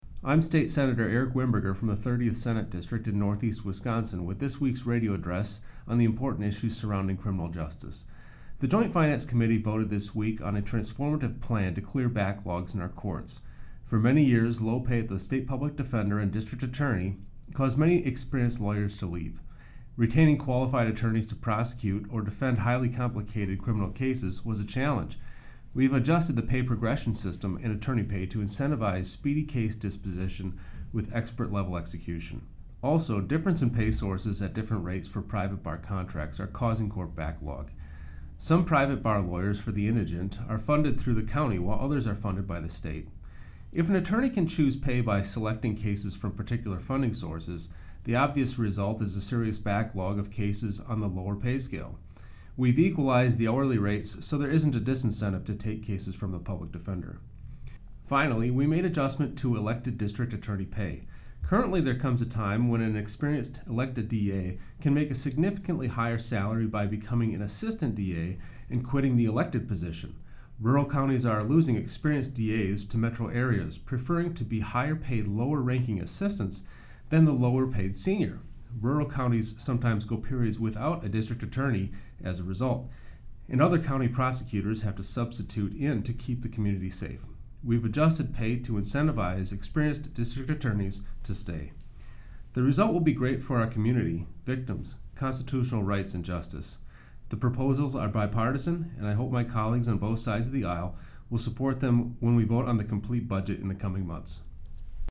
Weekly GOP radio address: Sen. Wimberger on Joint Finance Committee taking budget action on criminal justice - WisPolitics